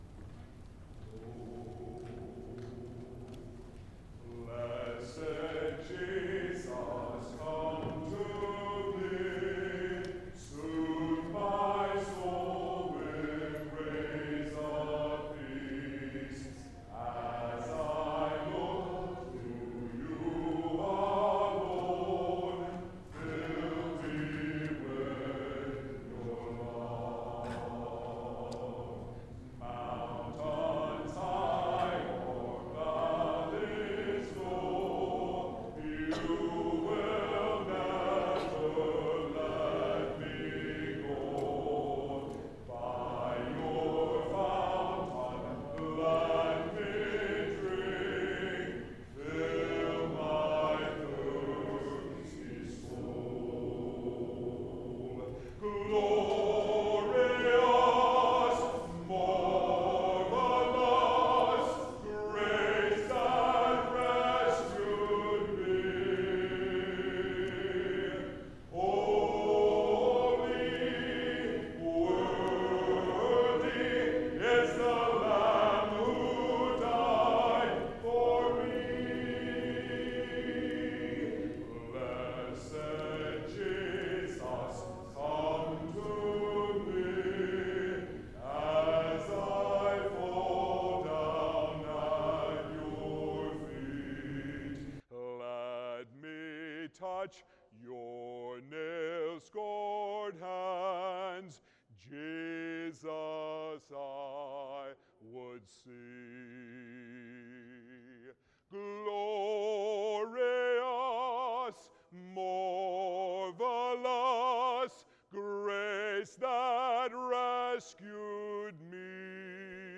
Church Gospel Singing - A Night of Praise & Worship | Mining The Scripture